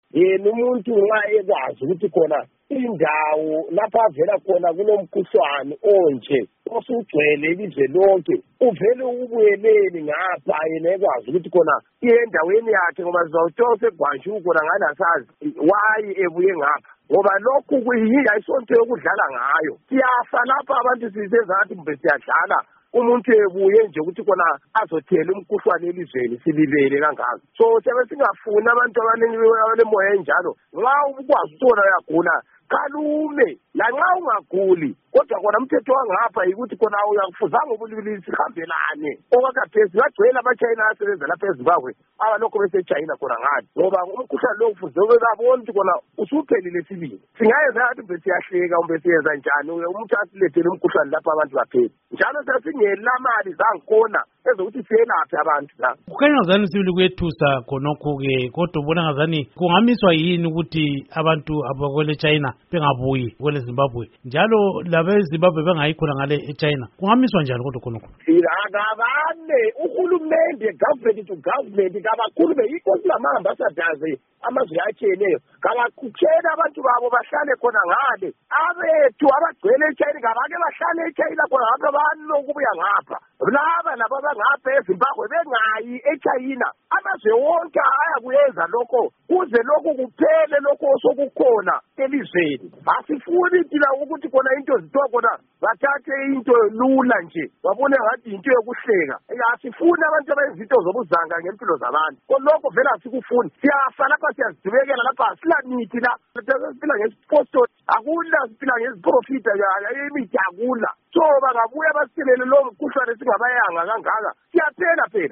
Ingxoxo Esiyenze LoMnu. Peter Moyo